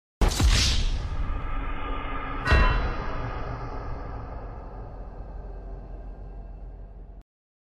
Free meme sound perfect for TikTok, YouTube, or social media projects.